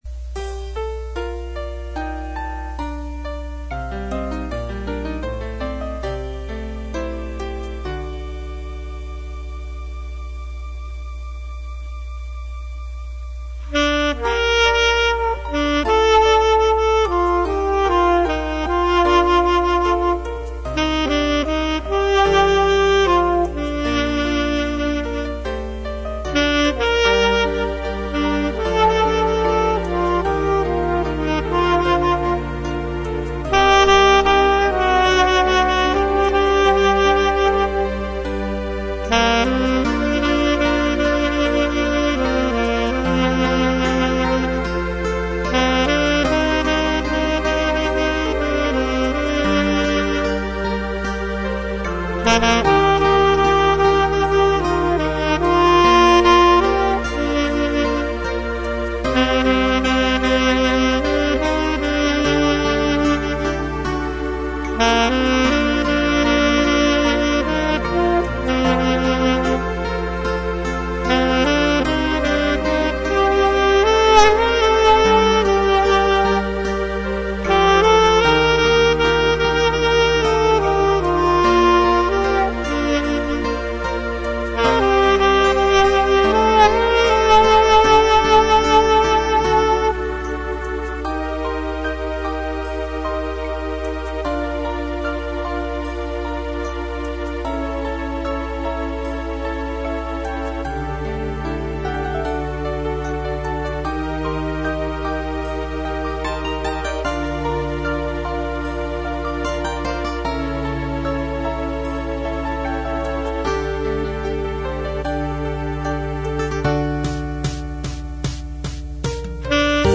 (앨토)